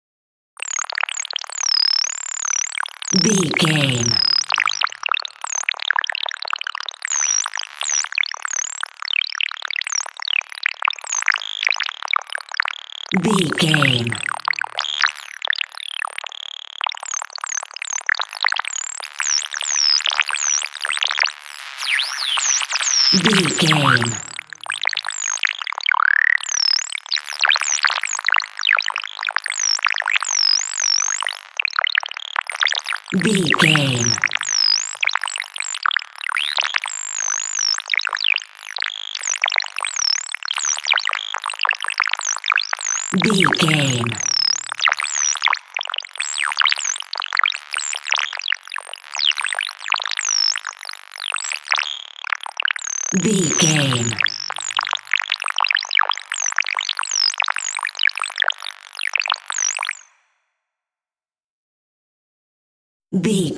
Atonal
Slow
scary
tension
ominous
dark
eerie
synthesiser
Horror synth
Horror Ambience
electronics